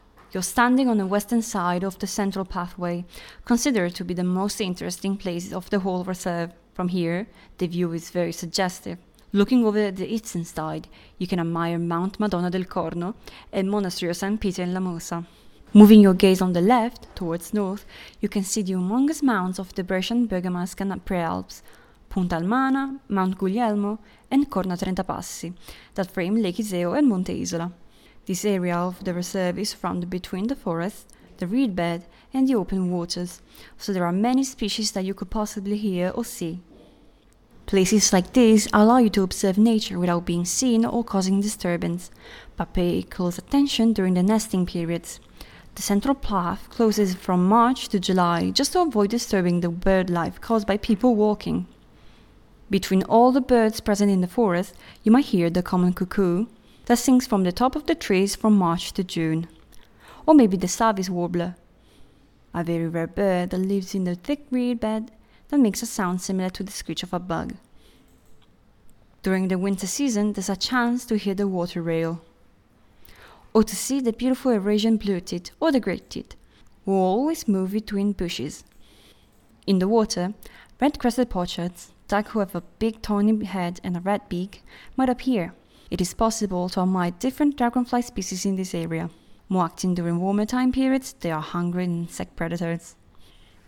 Audioguide 9